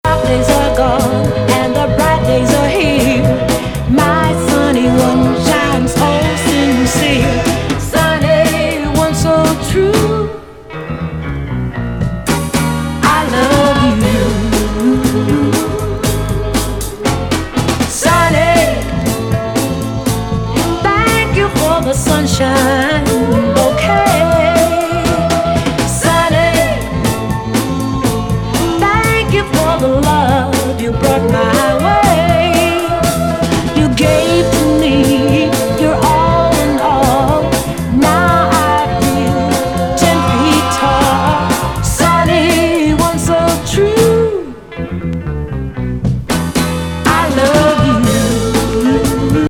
姉御レディ・ソウル・シンガー